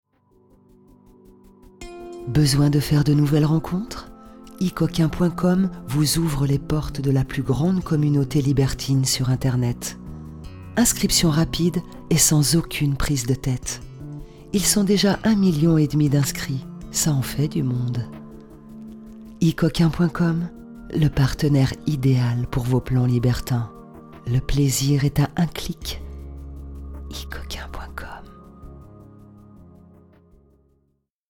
comédienne voix off / doublage
Kein Dialekt
Sprechprobe: Sonstiges (Muttersprache):